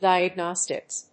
di・ag・nos・tics /dὰɪəgnάstɪks‐nˈɔs‐/
• / dὰɪəgnάstɪks(米国英語)
• / ˌdaɪʌˈgnɑ:stɪks(英国英語)